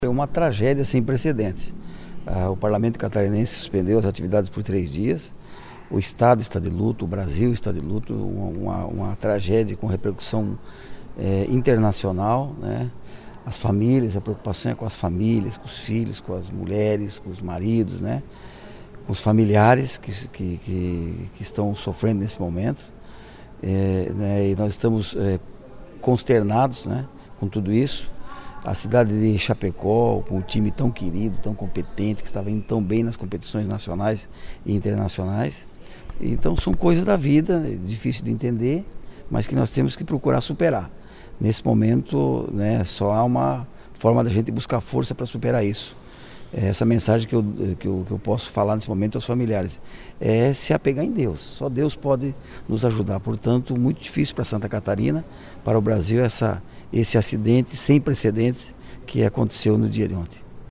Ouça, abaixo, o depoimento dos parlamentares: